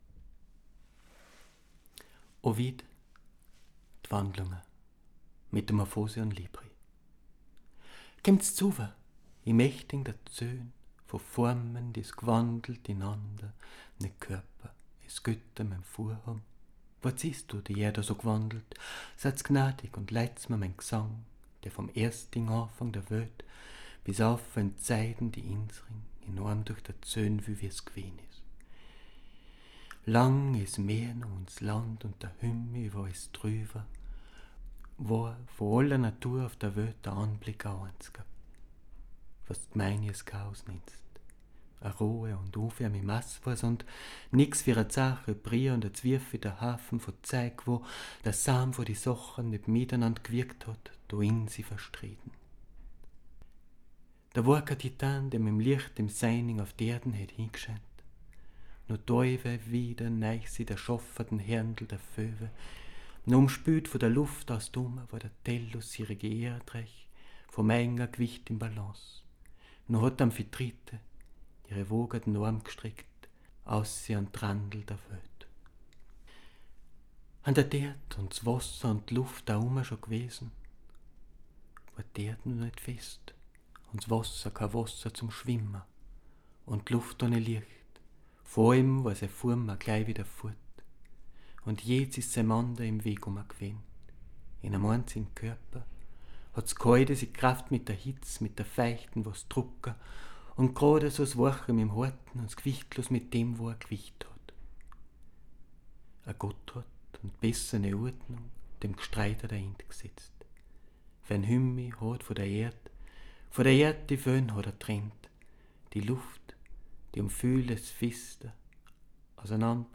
Denn, wie bei den anderen Übertragungen, sollte das laut (vor-)gelesen werden [können(?)] auch hier im Vordergrund stehn.
- ♫ Erste Audio-Skizze - (one-take recording - verleser ausgschnittn) ♫